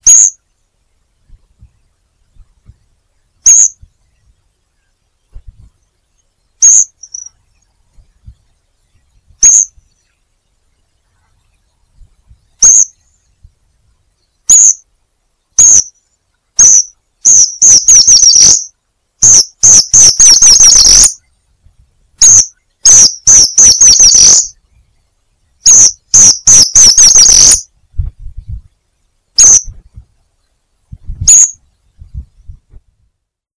Empidonomus varius - Tuquito rayado